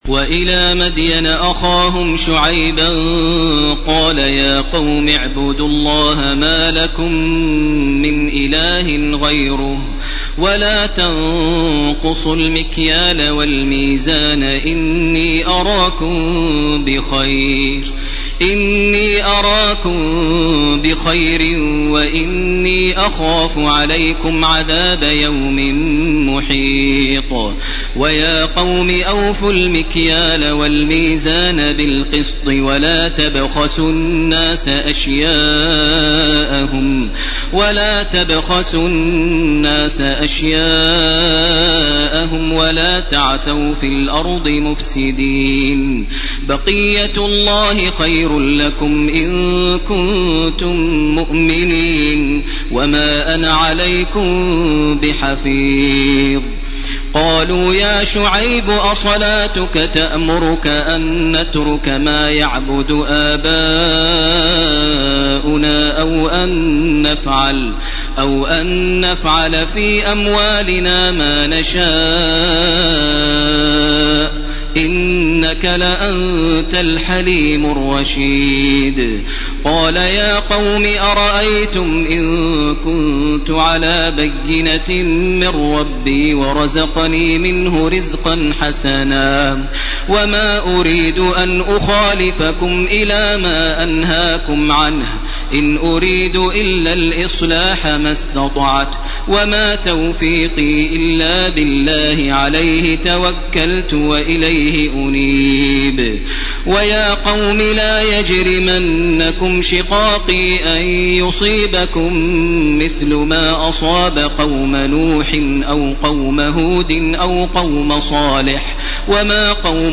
ليلة 12 رمضان لعام 1431 هـ من الآية 84 من سورة هود وحتى الآية 57 من سورة يوسف. > تراويح ١٤٣١ > التراويح - تلاوات ماهر المعيقلي